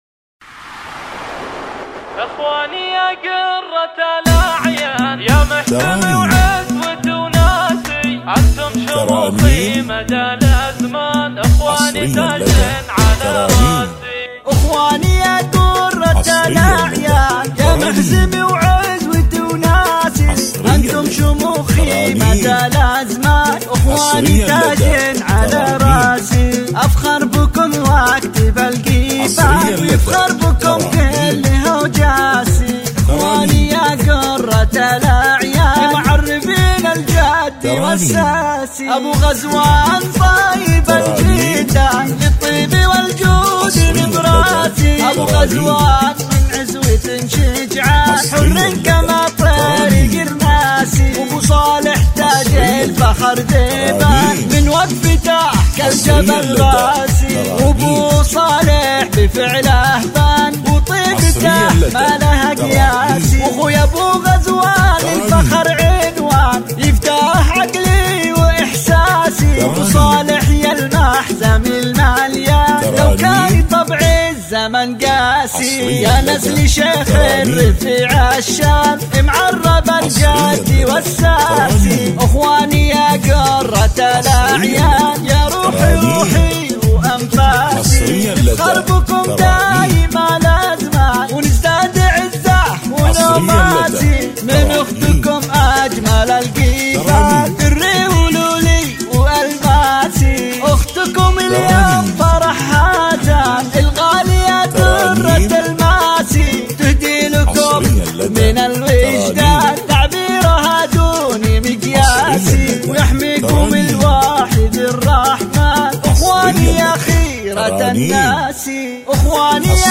شيلات تخرج 2020 زفة شيلات جديده وحصريه بدون موسيقى